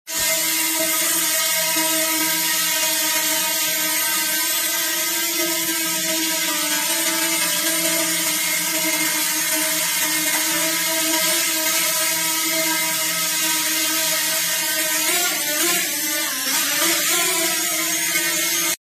Drone Dji Mini 2 Fly Sound Effects Free Download